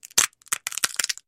Звук, когда Щелкунчик раскалывает орех